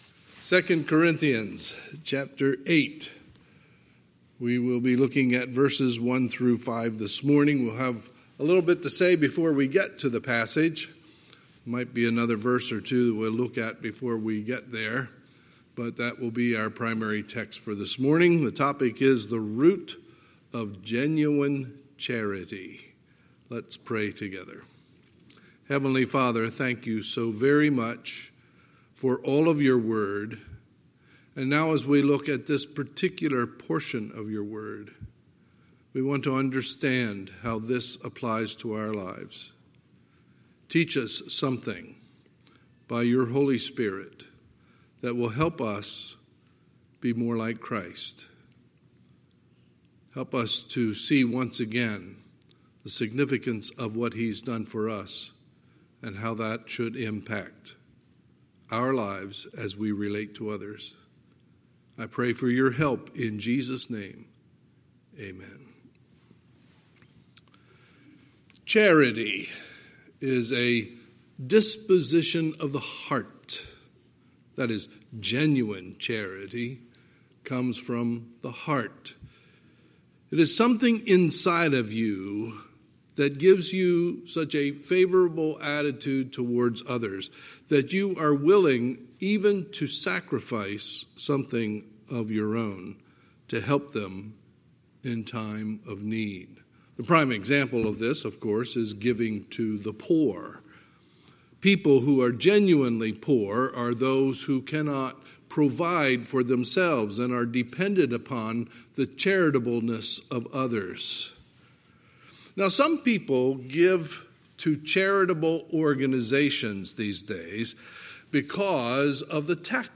Sunday, August 2, 2020 – Sunday Morning Service